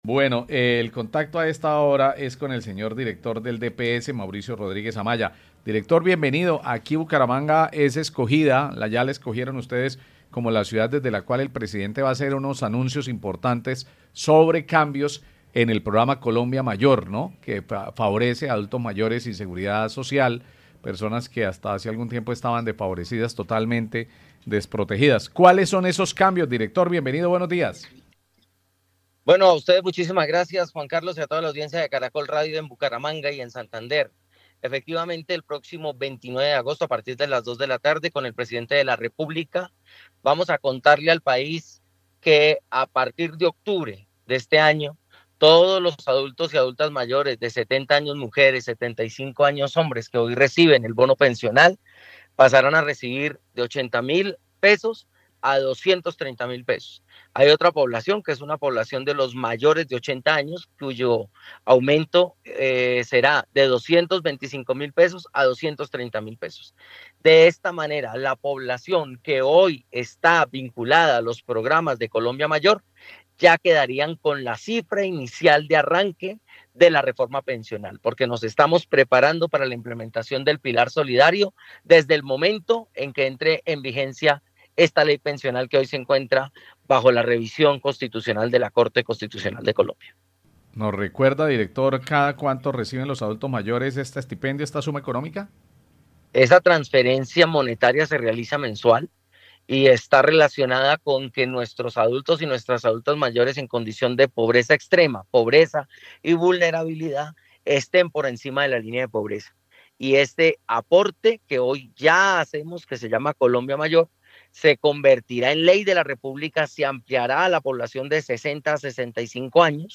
Mauricio Rodríguez, director del Departamento de Prosperidad Social